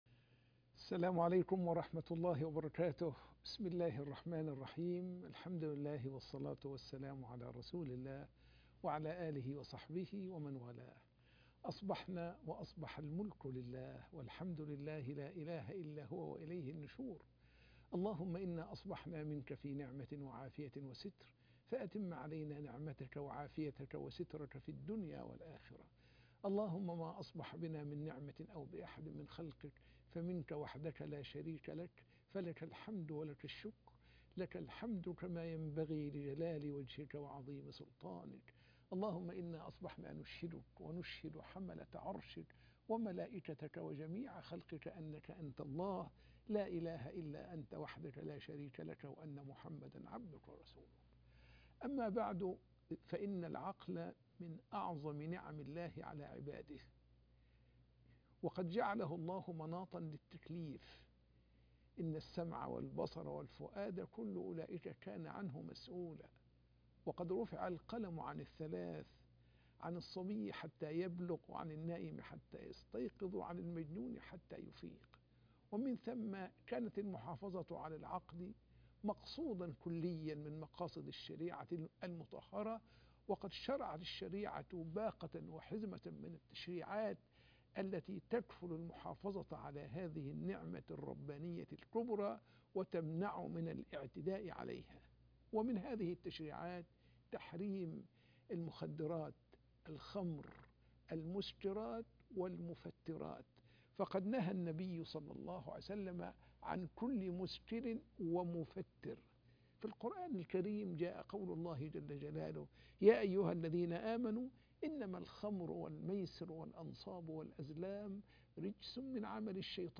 المخدرات وذهاب العقل - درس بعد الفجر